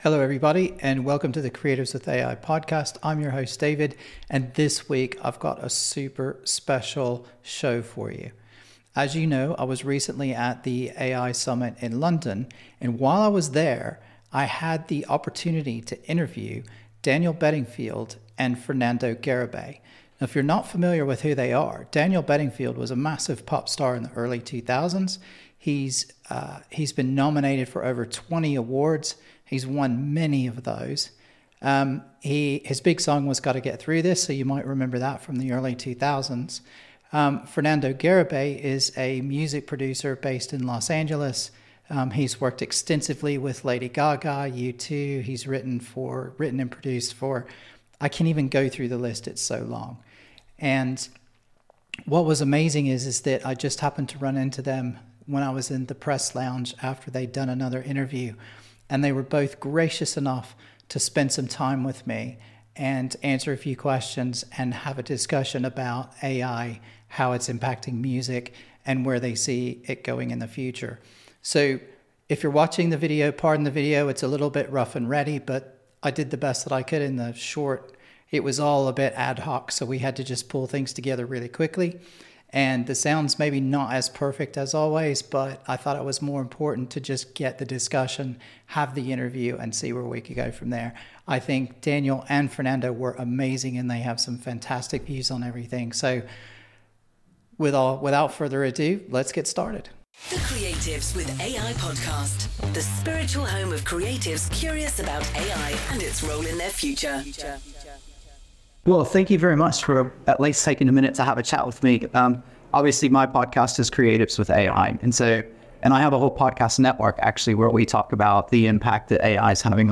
✨ Meet Our Guests: Daniel Bedingfield and Fernando Garibay Join us for an exclusive conversation with two luminaries in the music industry: Daniel Bedingfield, the chart-topping singer-songwriter, and Fernando Garibay, the Grammy-winning producer behind some of the music industry’s biggest artists.